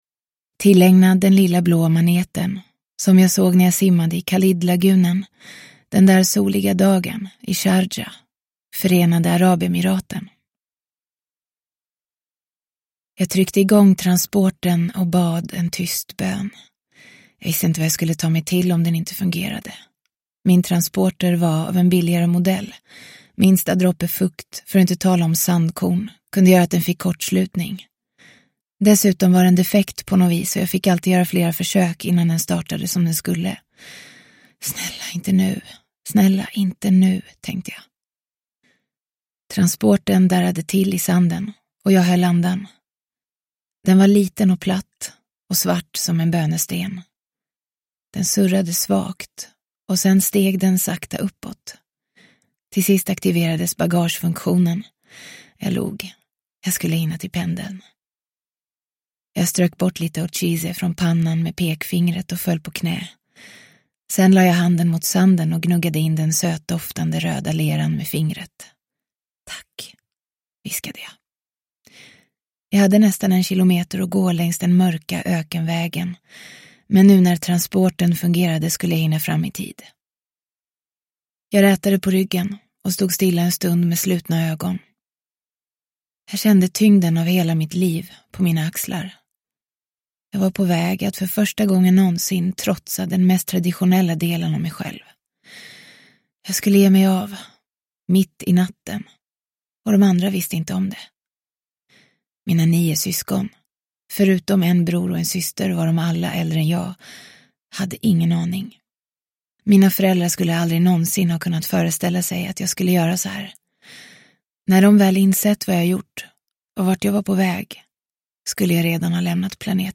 Binti 1: Ensam – Ljudbok – Laddas ner